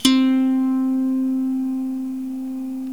SWEDISH LY03.wav